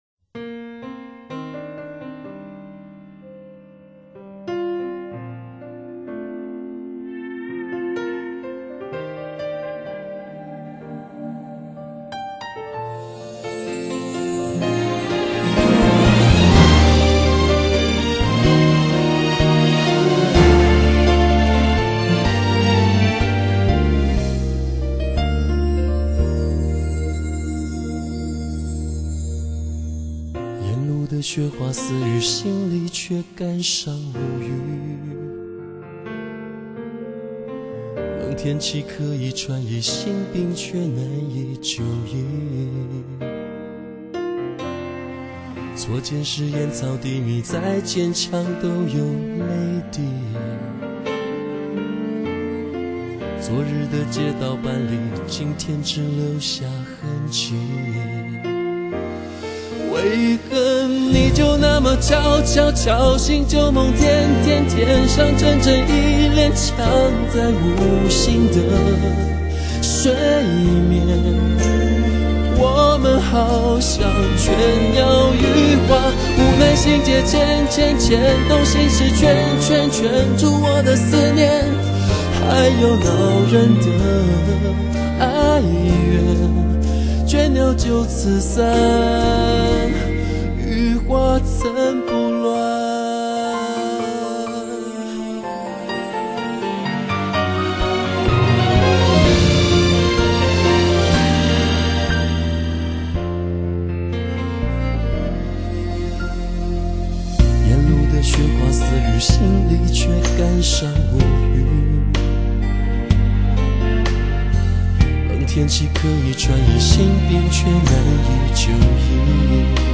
中国古典意境+西洋古典的配乐